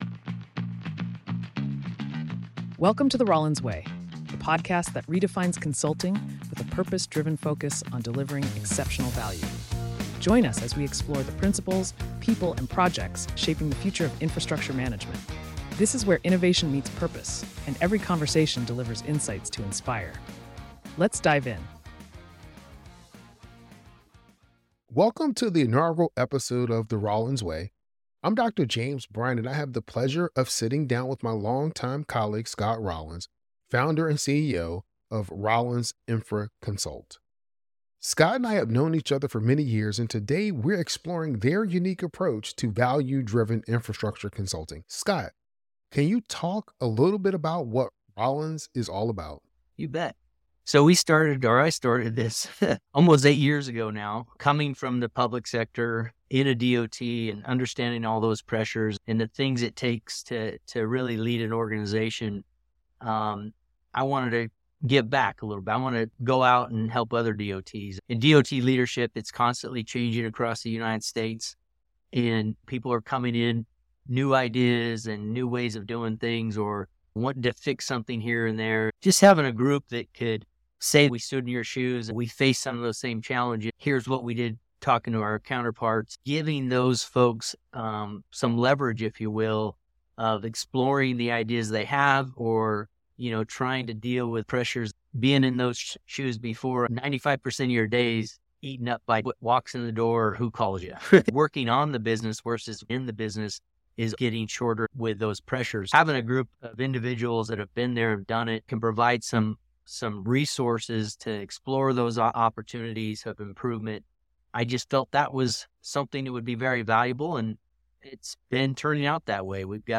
(Interview Edition)